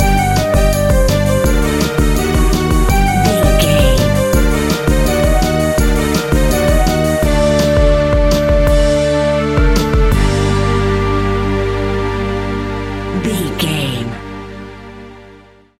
Aeolian/Minor
World Music
percussion
djembe